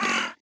ZomBunny Hurt.wav